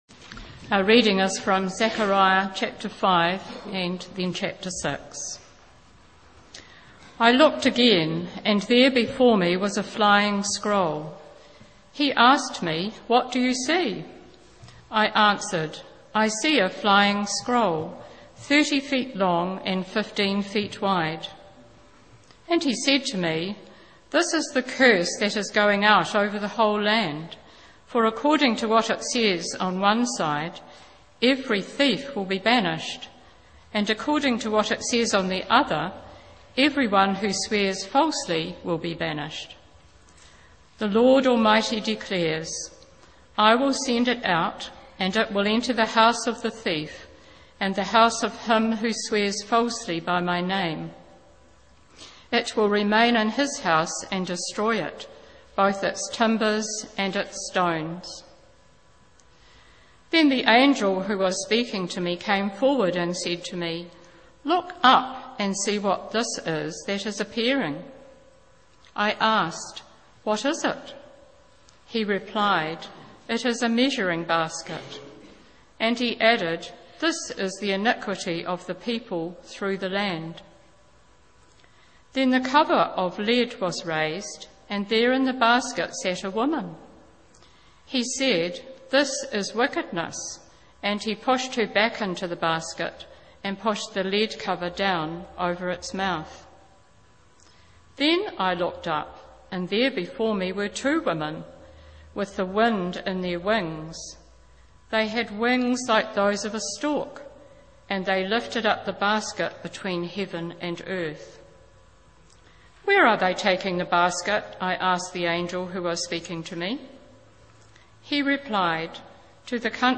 Sermons – St Stephen's Anglican Church | Christchurch